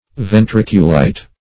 Ventriculite \Ven*tric"u*lite\, n. [See Ventriculus.]